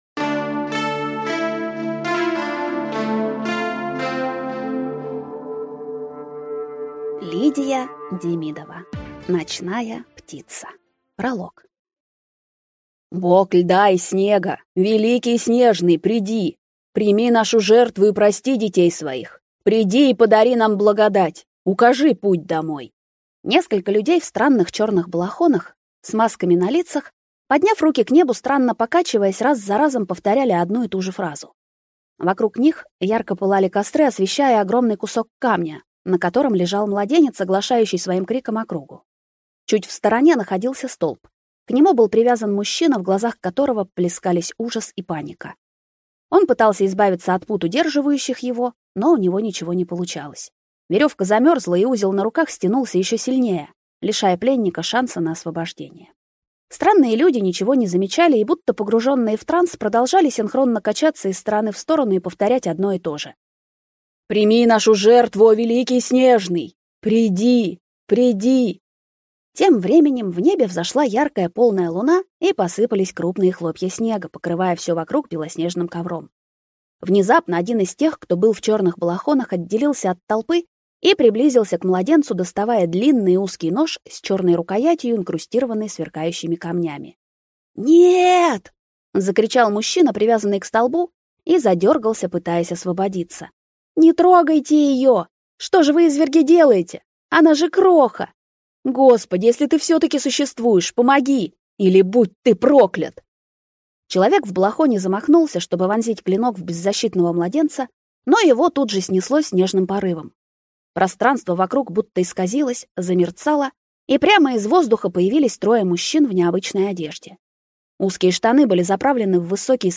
Aудиокнига Ночная птица